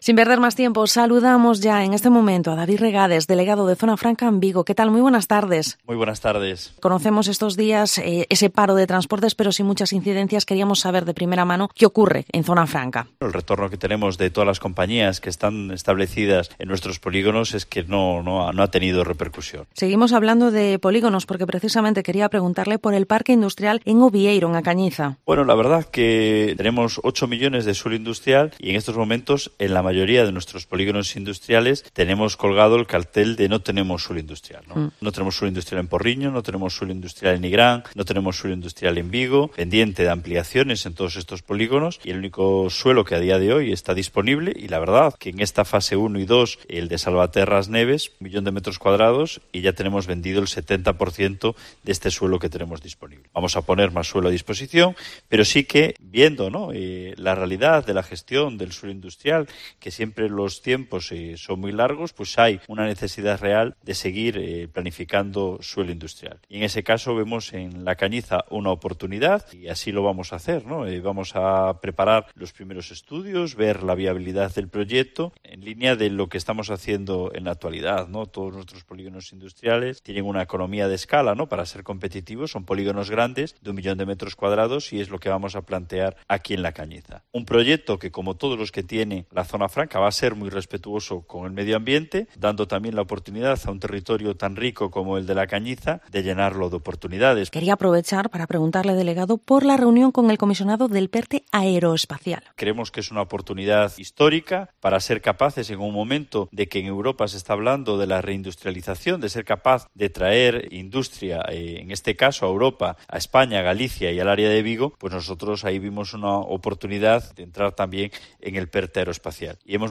Entrevista a David Regades, delegado de Zona Franca en Vigo